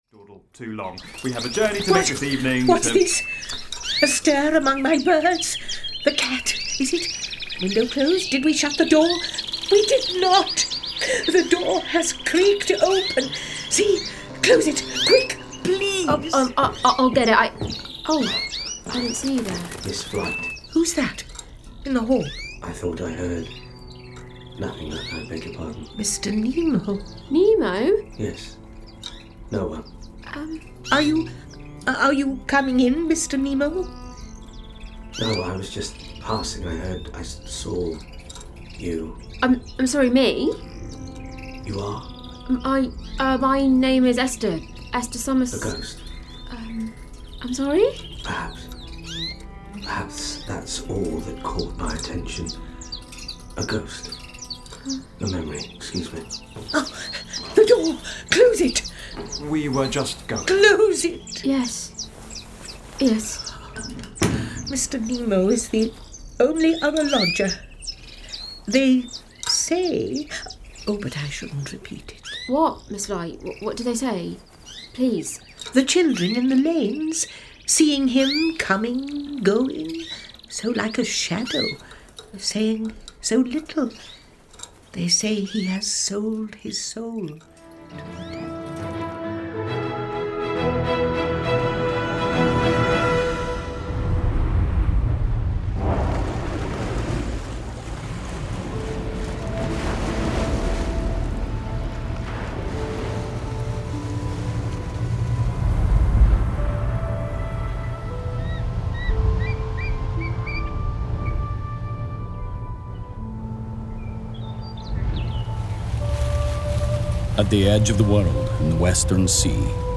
Audio Drama Montage for 2025.